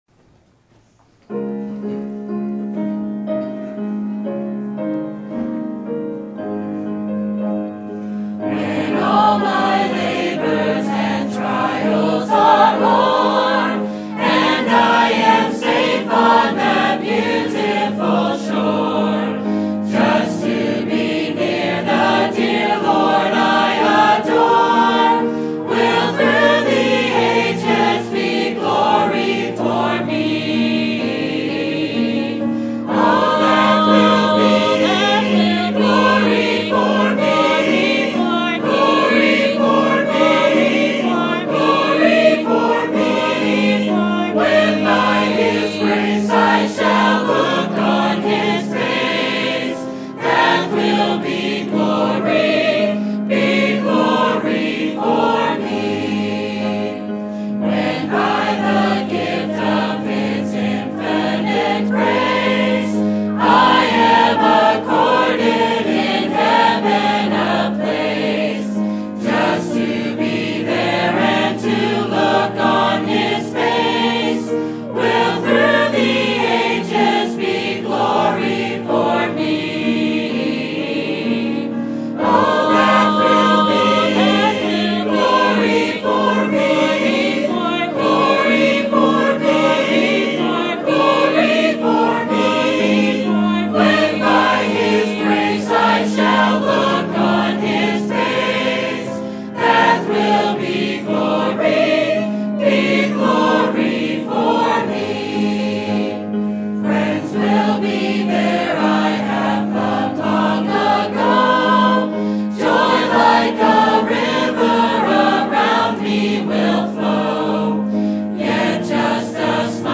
Sermon Topic: General Sermon Type: Service Sermon Audio: Sermon download: Download (22.29 MB) Sermon Tags: 1 John Love World God